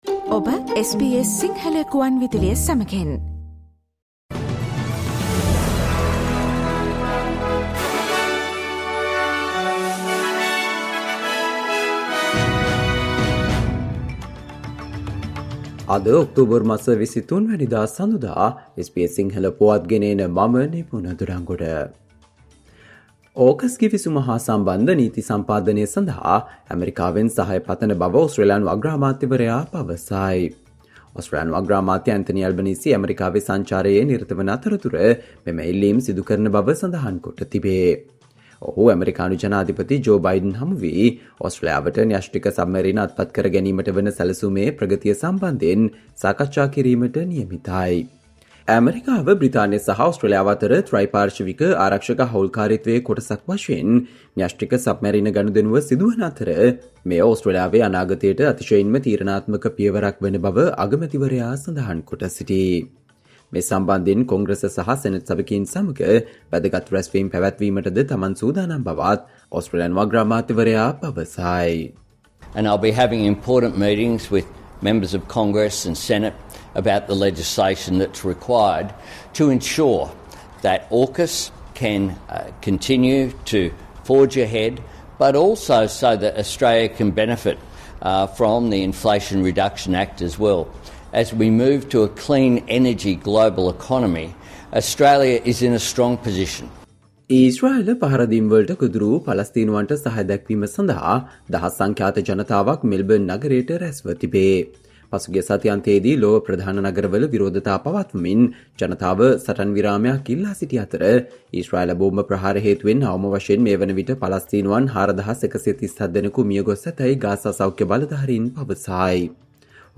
Australia news in Sinhala, foreign and sports news in brief - listen Sinhala Radio News Flash on Monday 23 October 2023.